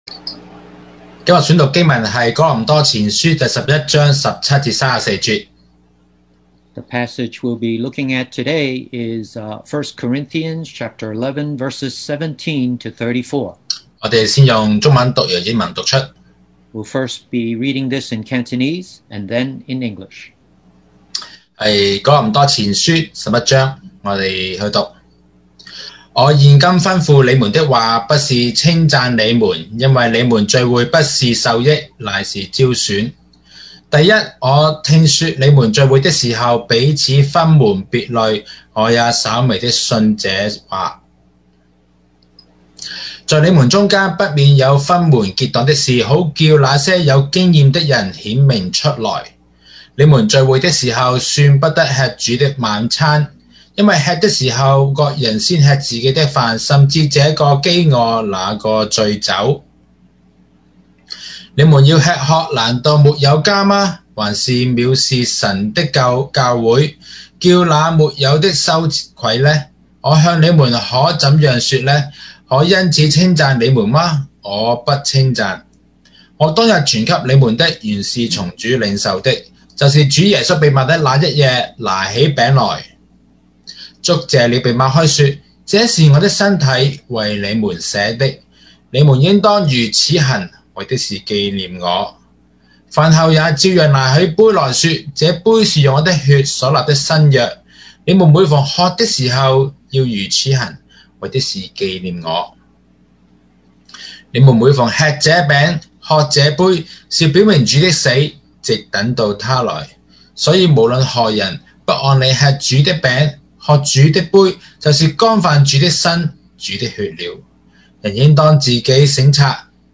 Series: 2020 sermon audios
Service Type: Sunday Morning